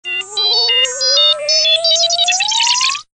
Звуки дроида R2D2 из звёздных войн в mp3 формате
6. Крик